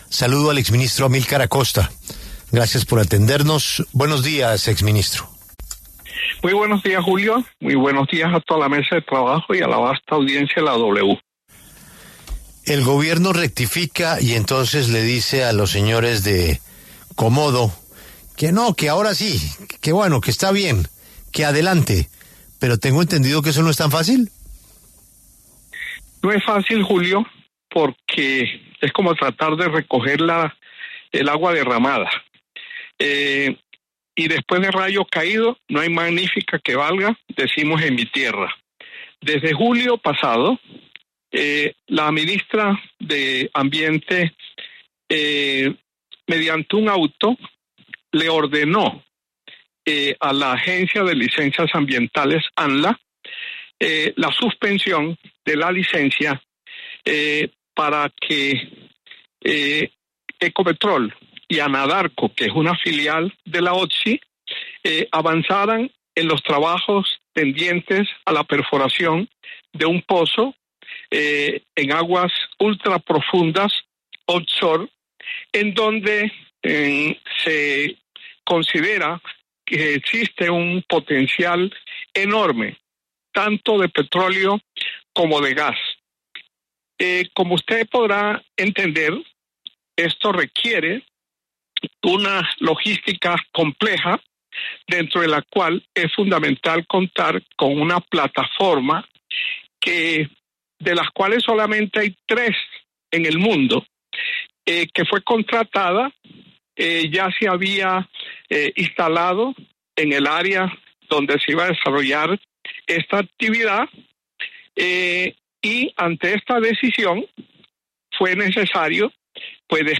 En entrevista con La W, el exministro de Minas y Energía Amylkar Acosta explicó los motivos que llevarían a que el pozo gasífero Komodo – 1, que se desarrolla entre Ecopetrol y Anadarko filial de OXY, presente retrasos en su proceso de exploración a pesar de que tanto la Agencia Nacional de Licencias Ambientales (Anla) como el Ministerio de Ambiente ya dieron sus avales y respectiva licencia para avanzar.